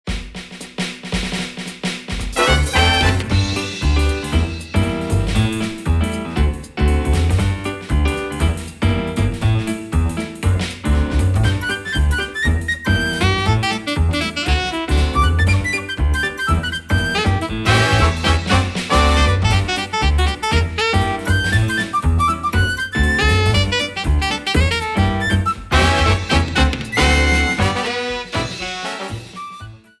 Shortened, applied fade-out and converted to oga